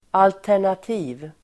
Uttal: [altär_nat'i:v (el. 'al:-)]
alternativ.mp3